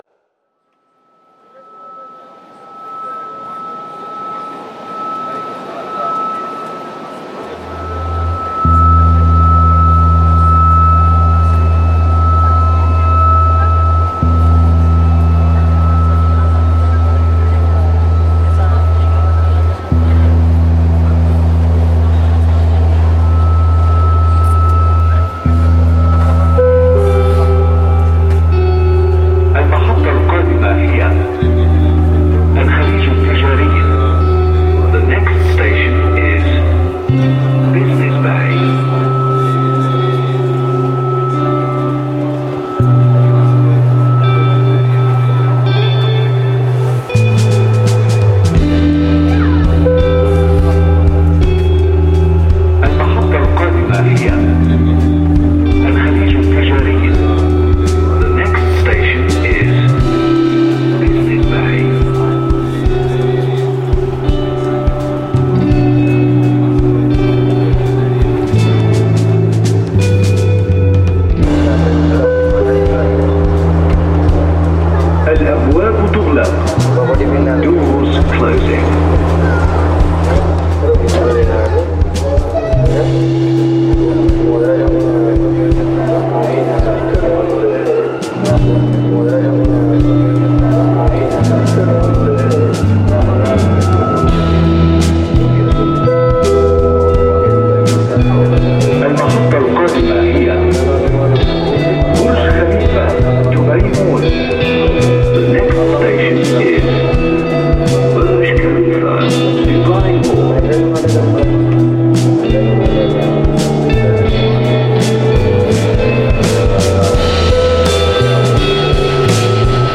guitar-based, post-rock feel